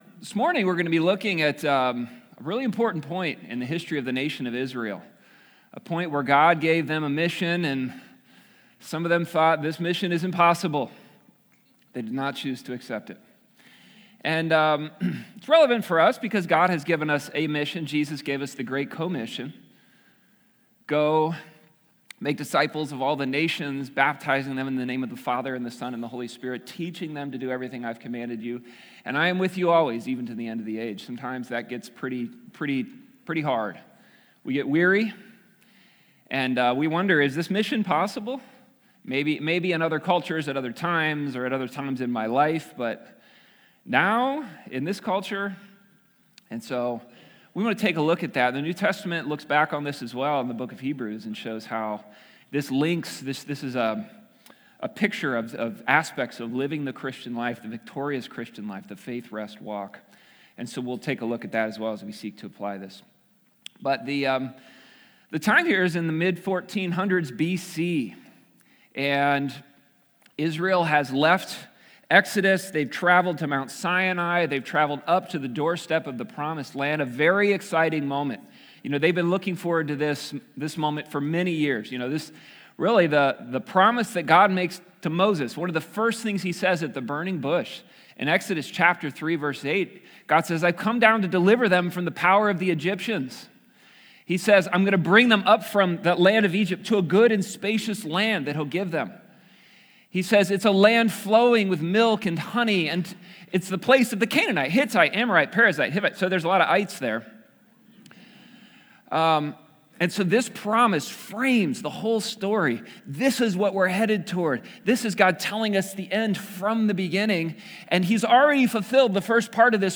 MP4/M4A audio recording of a Bible teaching/sermon/presentation about Numbers 13-14.